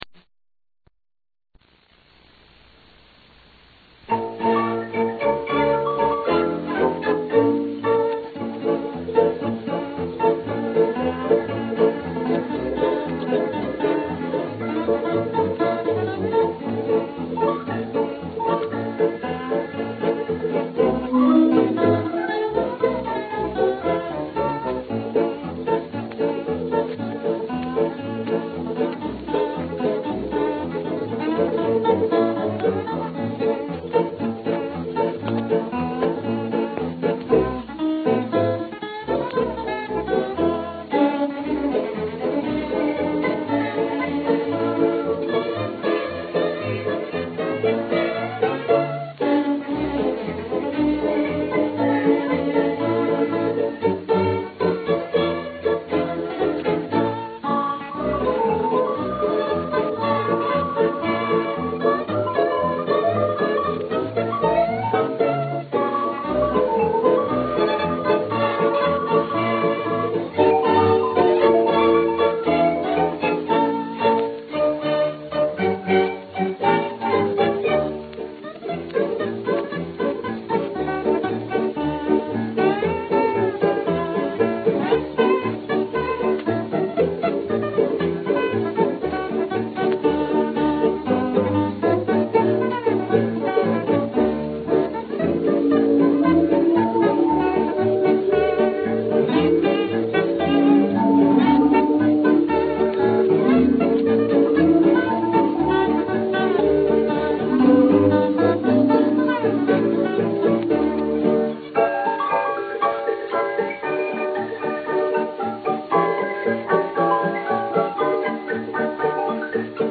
A terrific Hawaiian march